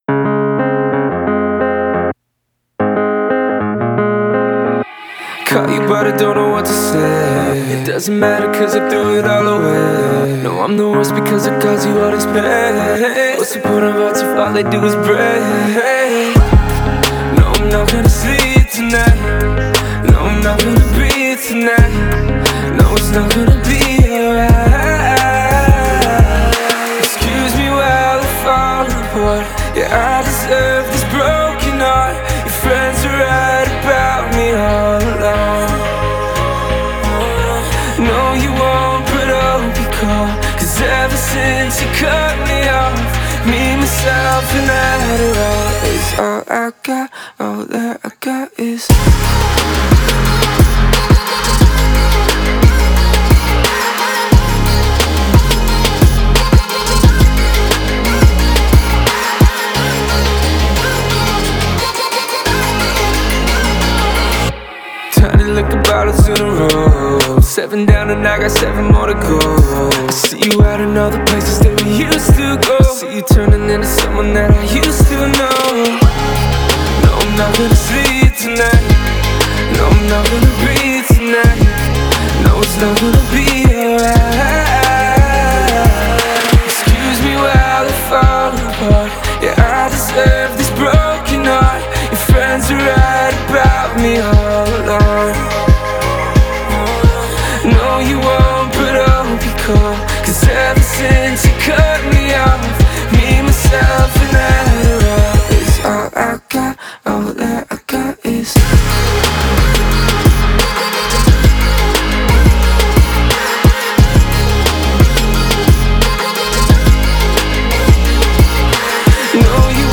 Эмоциональный вокал